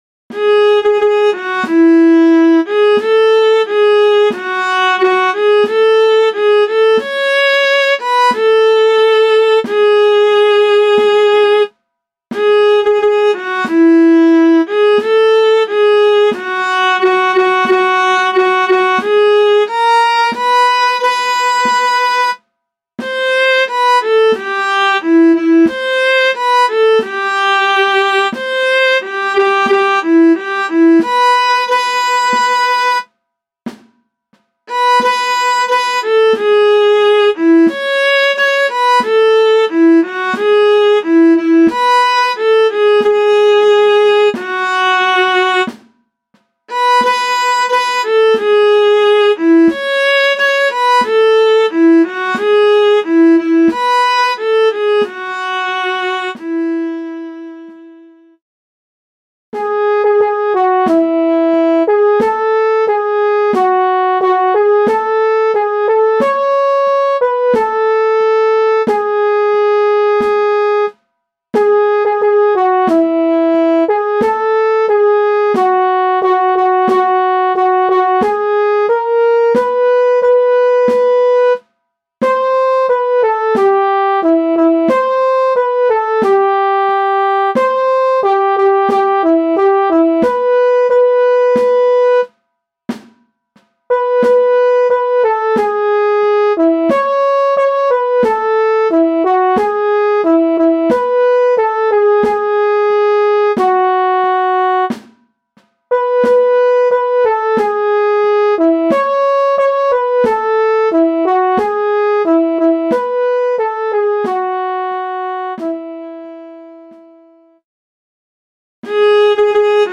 MIDI - 1-stimmig
midi_in-das-weite_1-stimmig_320.mp3